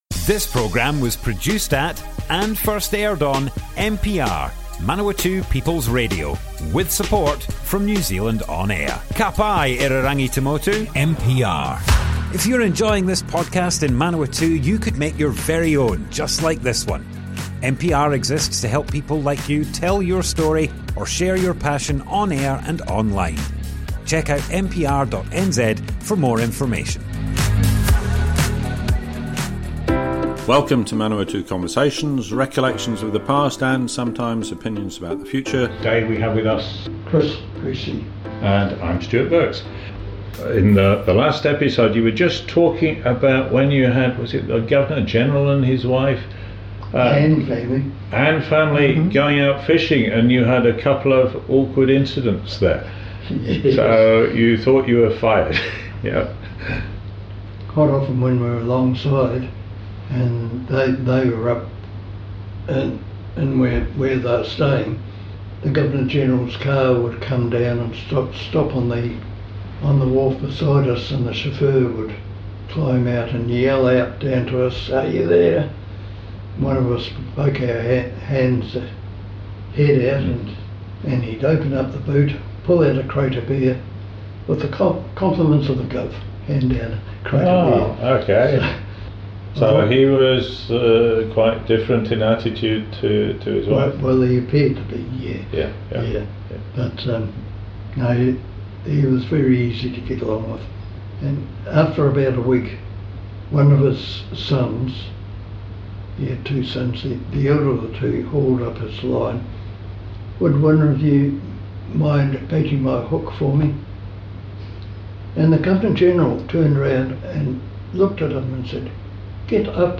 Manawatu Conversations More Info → Description Broadcast on Manawatu People's Radio, 17th June 2025.
oral history